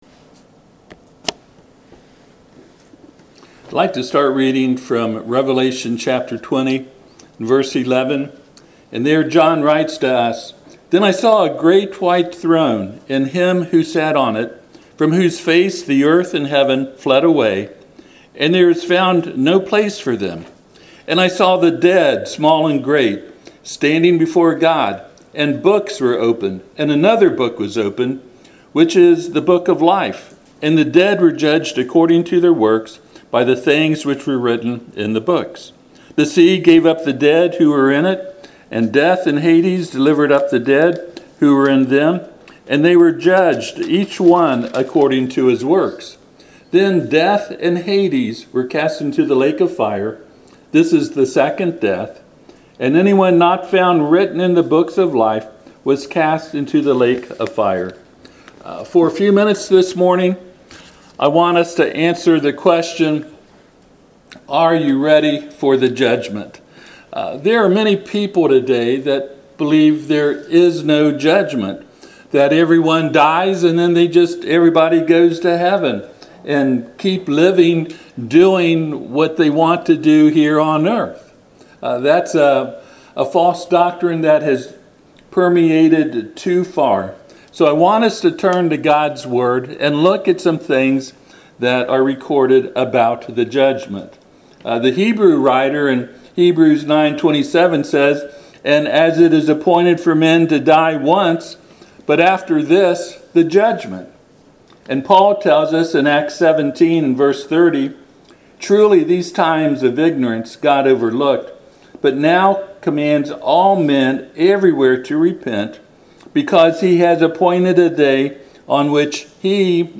Passage: Revelation 20:11-15 Service Type: Sunday AM